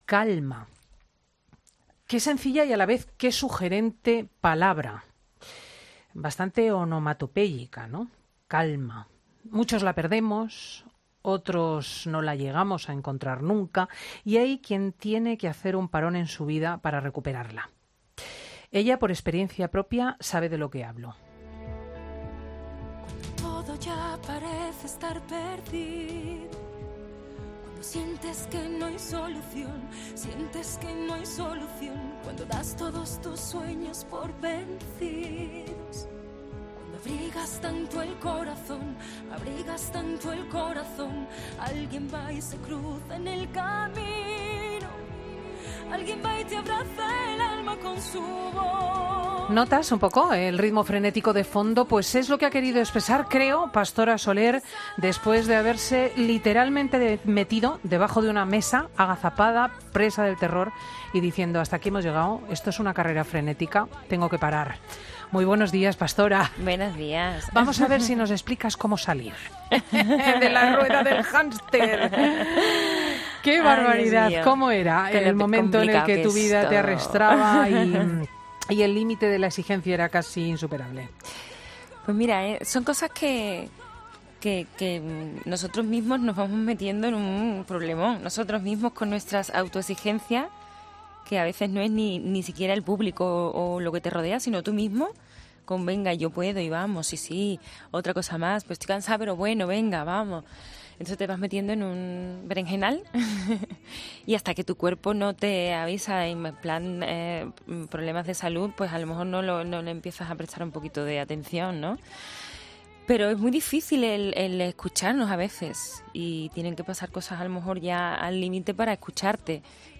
Pastora Soler ha estado en 'Fin de Semana COPE' con Cristina López Schlichting, donde ha explicado que ha recuperado la calma.